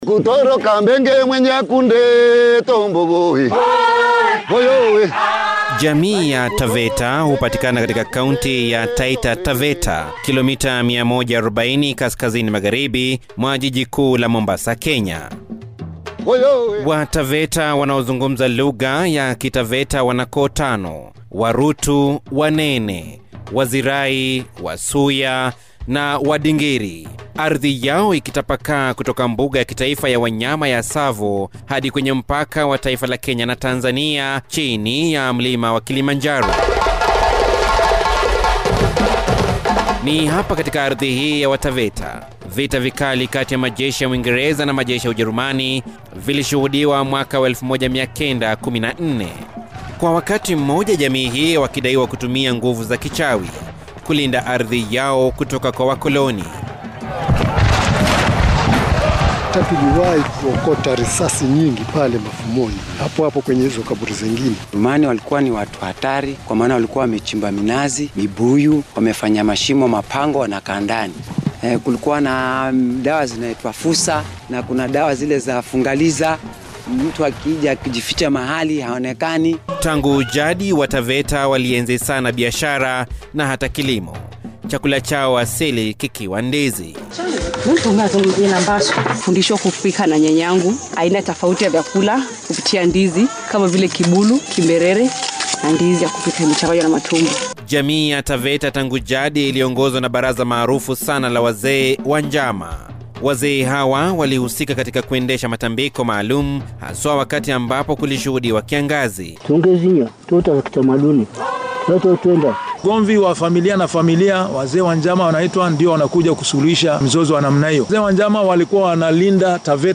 Radio Documentary: